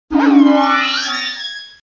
Fichier:Cri 0468 DP.ogg